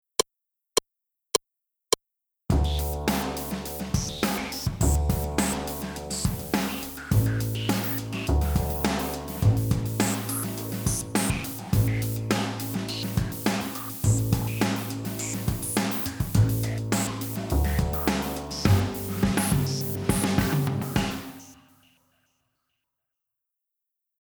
반주